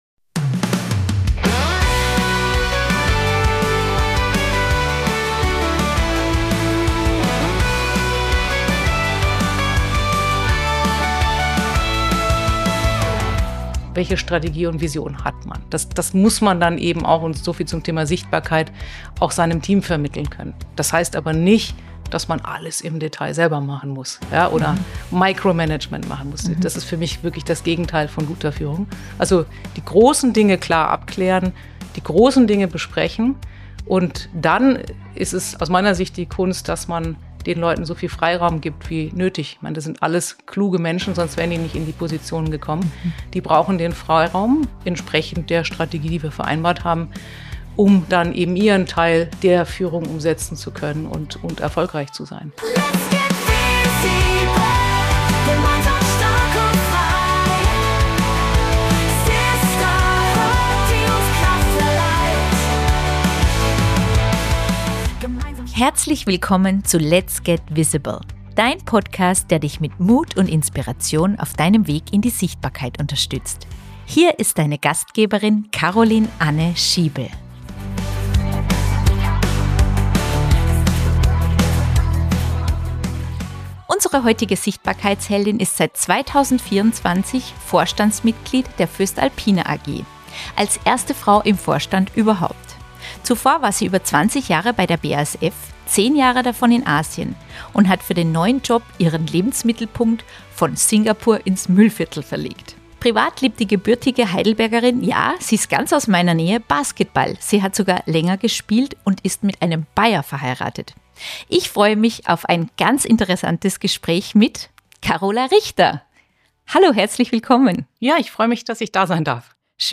Ein inspirierendes Gespräch für alle, die mehr Sichtbarkeit wollen, Verantwortung übernehmen möchten – und neugierig sind, wie moderne Leadership und weibliche Vorbilder die Industrie verändern.